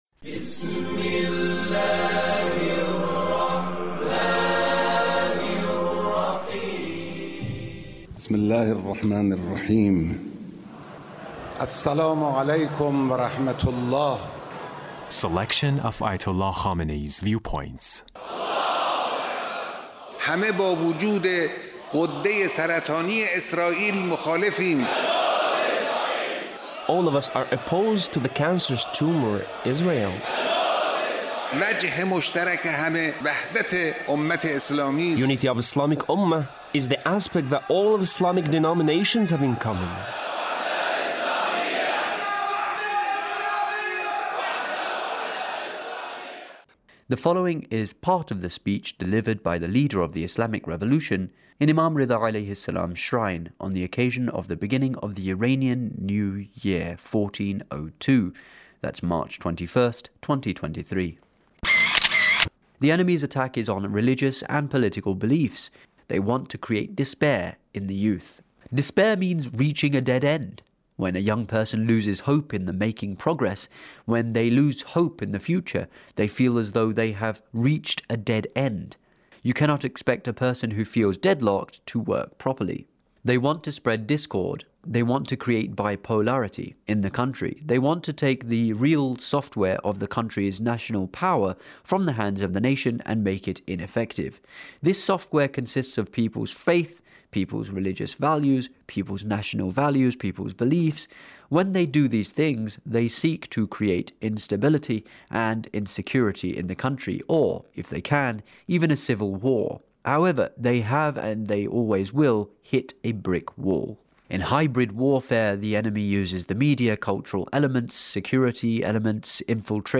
Leader's Speech on Norooz 1402